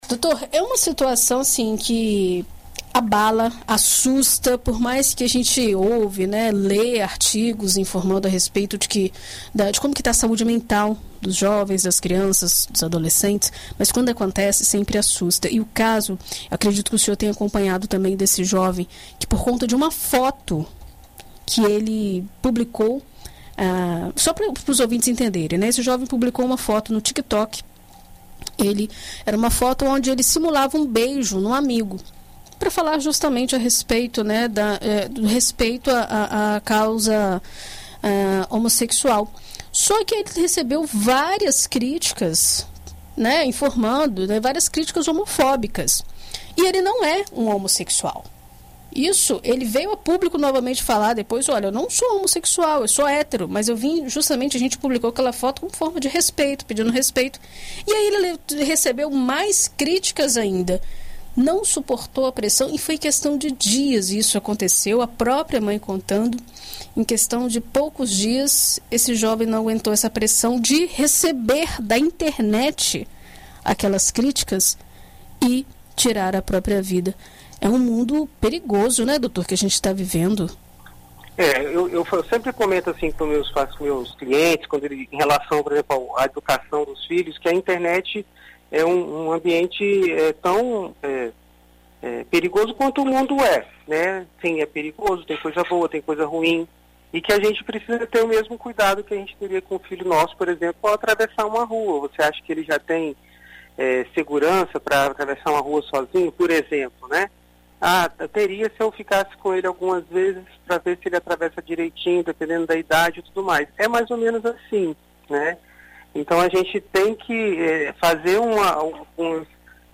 ENT-PSIQUIATRA.mp3